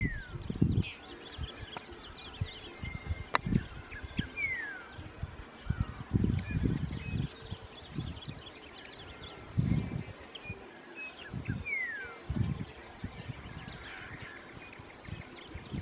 Bluish-grey Saltator (Saltator coerulescens)
Life Stage: Adult
Province / Department: Córdoba
Detailed location: Mirador Loma de los Indios
Condition: Wild
Certainty: Recorded vocal